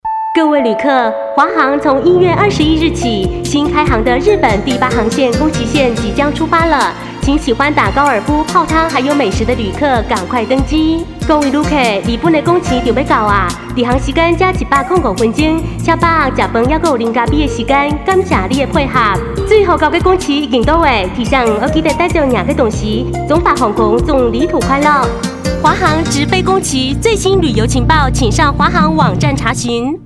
台語配音 國語配音 女性配音員 客語配音
華航-宮崎.廣播篇-國台客.30秒